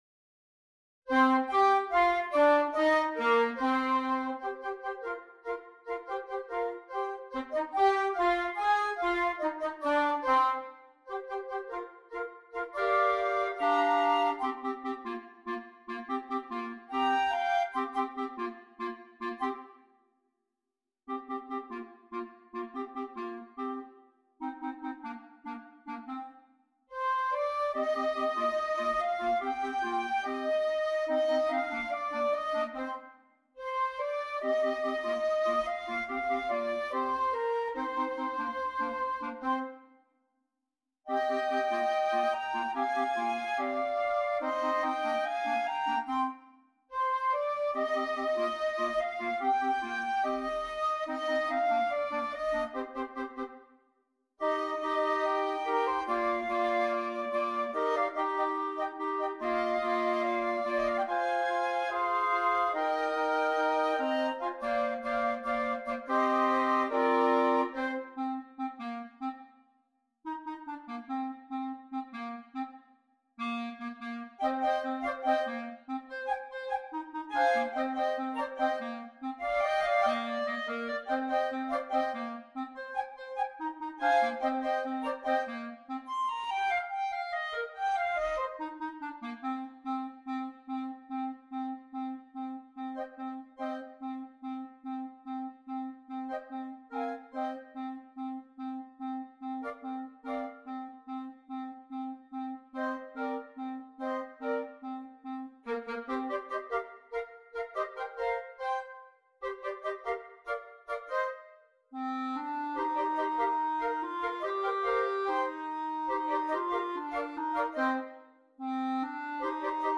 Voicing: 2 Flute and 2 Clarinet